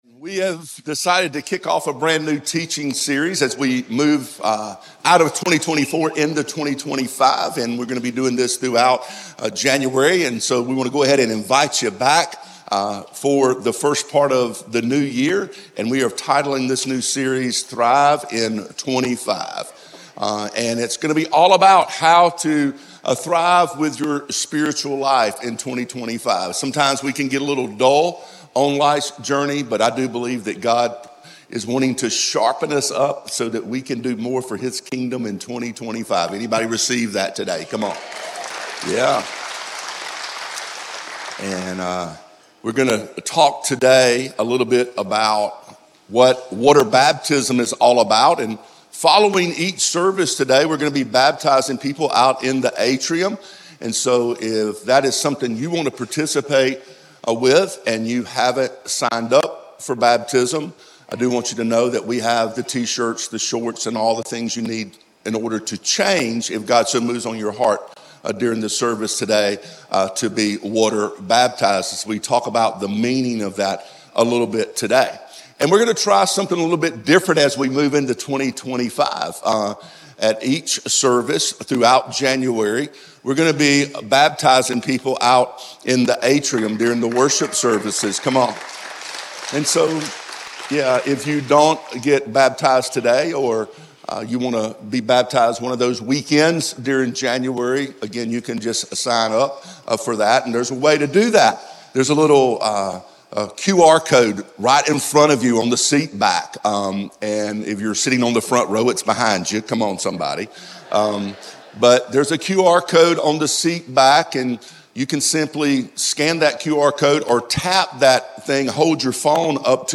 He is known for his ability to captivate and challenge the audience by delivering the Word of God in a way that is relevant to our world today.